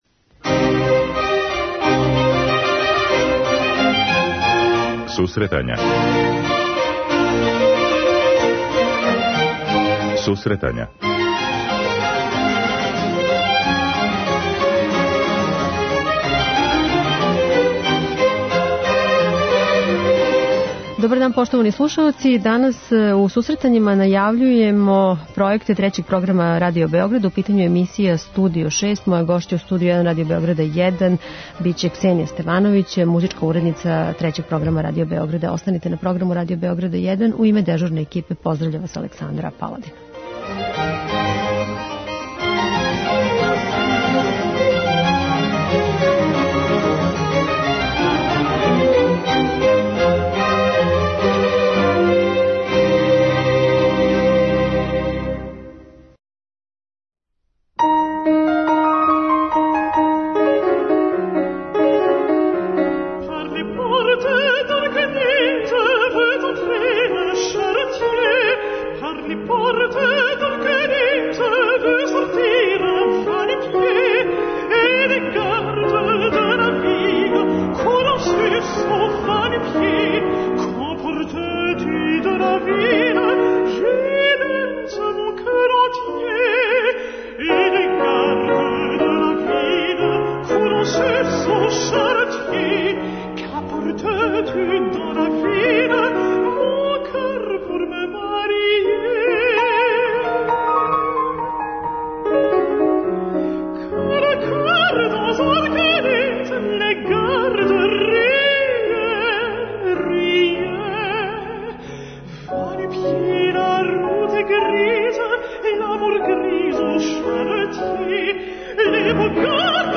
Трећи програм Радио Београда у сарадњи са каналом РТС 3 реализује уживо емисије из Студија 6 Радио Београда.